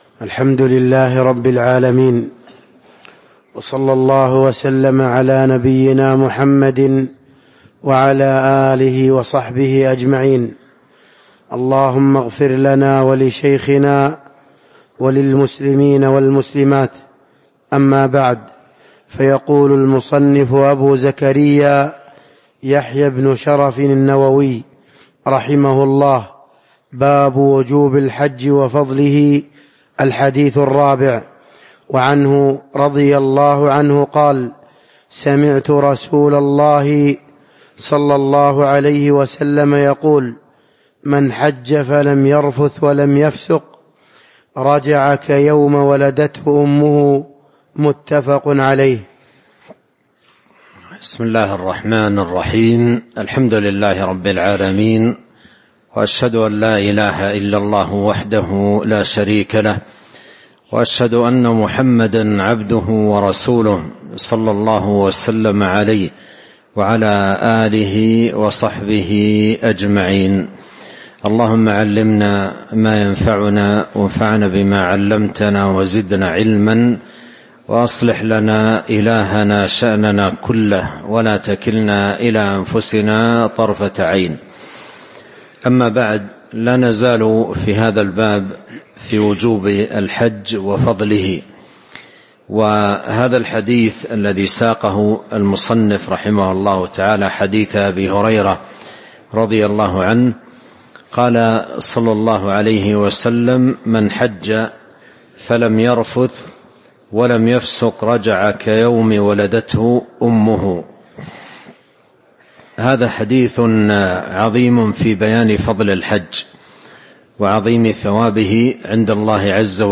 تاريخ النشر ١٩ جمادى الآخرة ١٤٤٥ هـ المكان: المسجد النبوي الشيخ